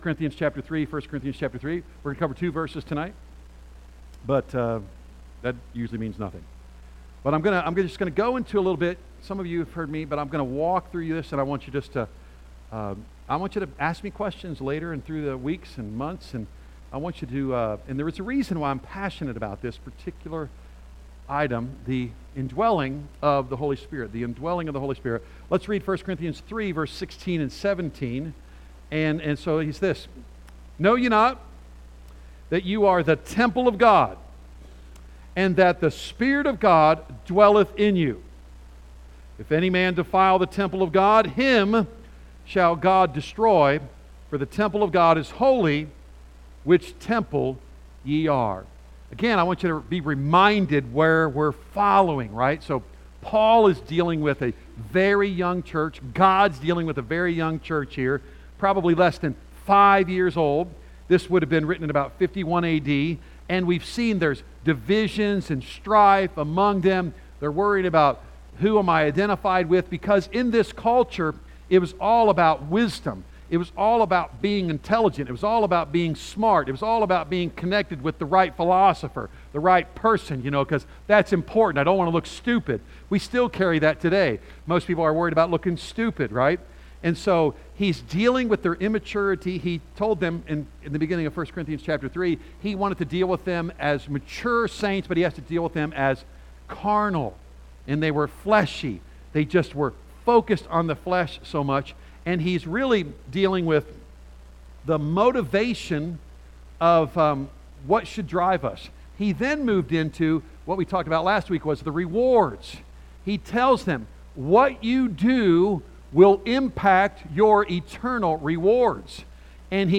A message from the series "1 Corinthians."